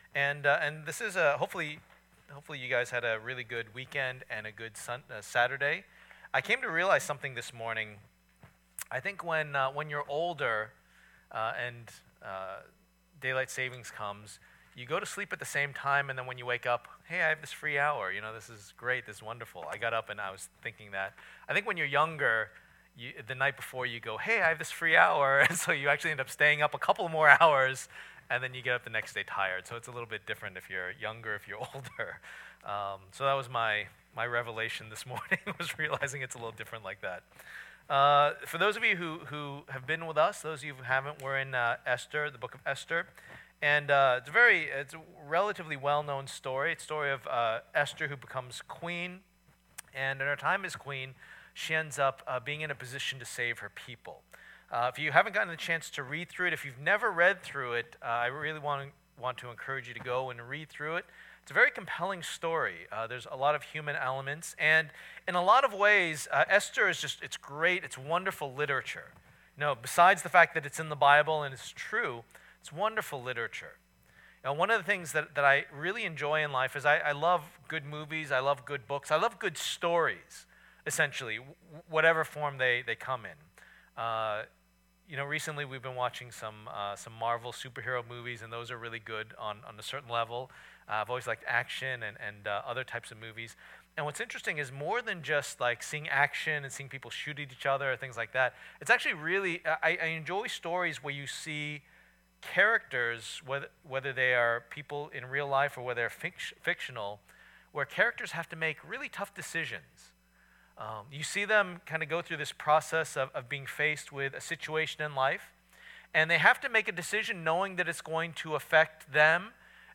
2016 Esther’s Tough Decision Preacher